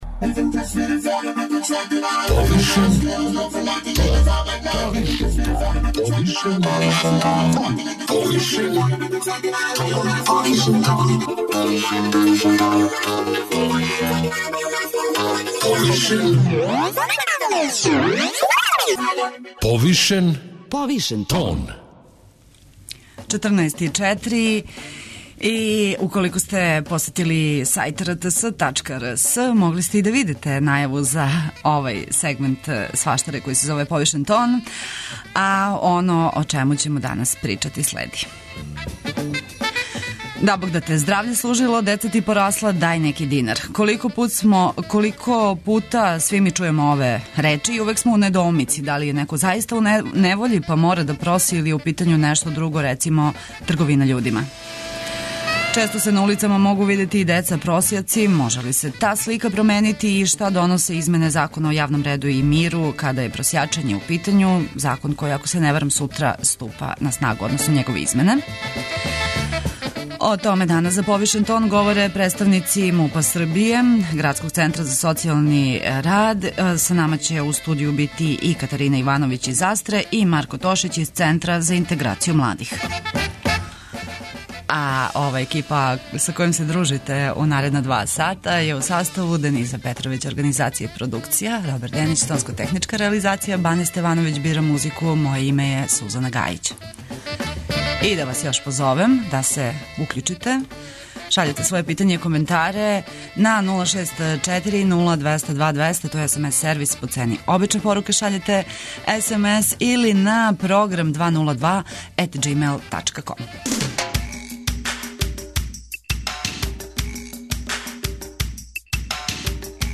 Често се на улицама могу видети и деца просјаци... може ли се та слика променити и шта доносе измене Закона о јавном реду и миру када је просјачење у питању. О томе за Повишен тон говоре представници МУП-а Србије, Градског центра за социјални рад и невладиних организација.